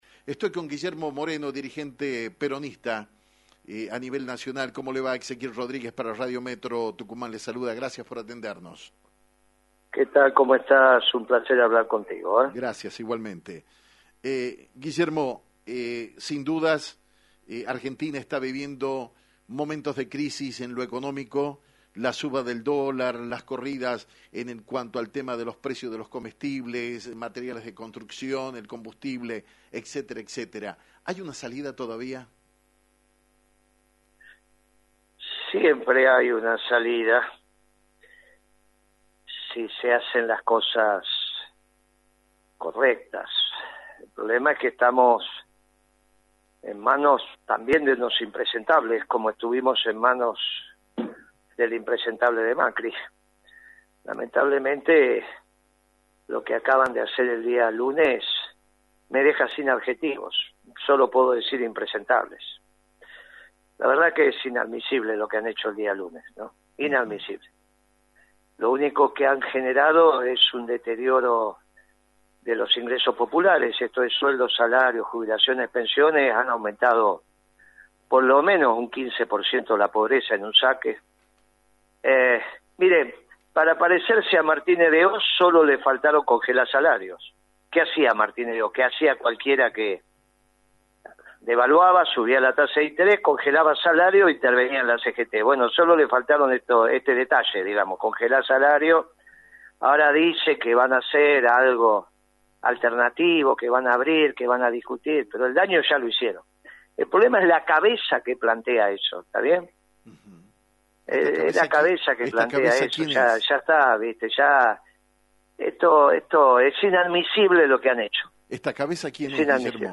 En dialogo exclusivo con Actualidad en Metro